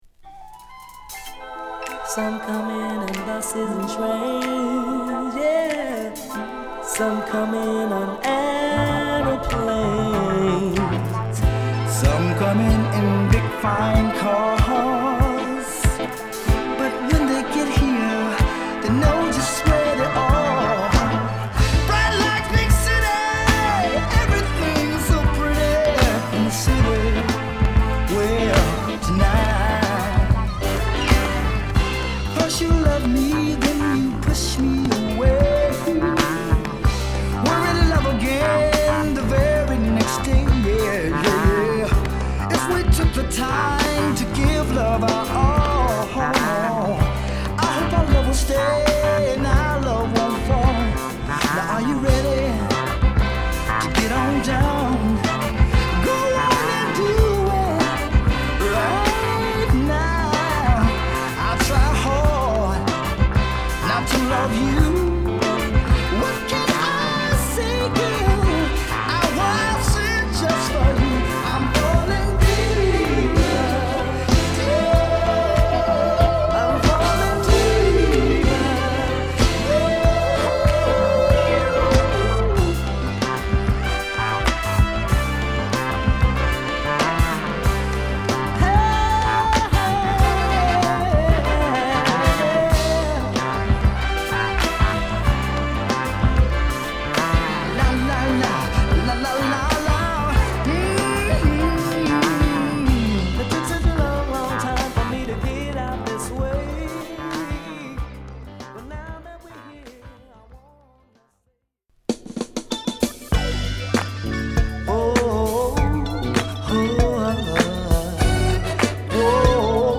＊A1頭に傷有り